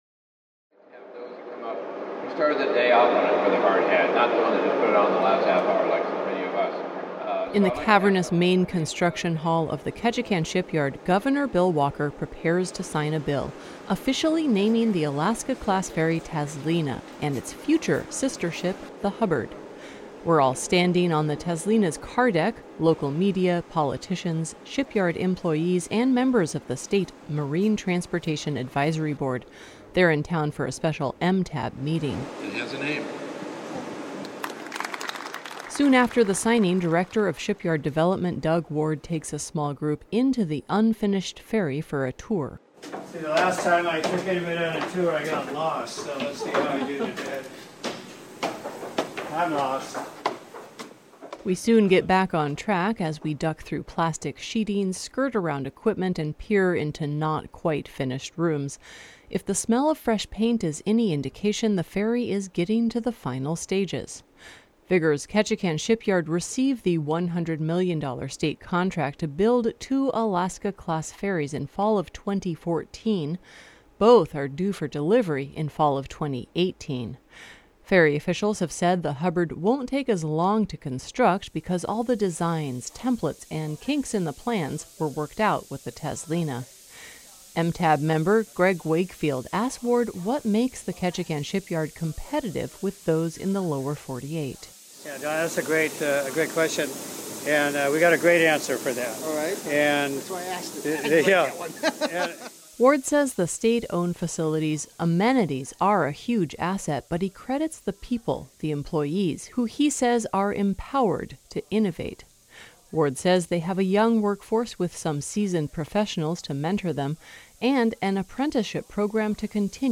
In the cavernous main construction hall of the Ketchikan shipyard, Gov. Bill Walker prepares to sign a bill officially naming the Alaska-class ferry Tazlina, and its future sister ship the Hubbard.
We soon get back on track, as we duck through plastic sheeting, skirt around equipment and peer into not-quite finished rooms.